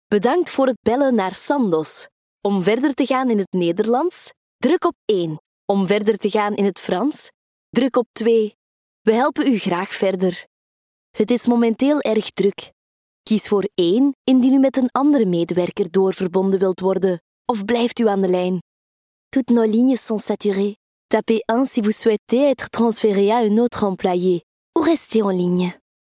Junge, Verspielt, Zuverlässig, Freundlich
Telefonie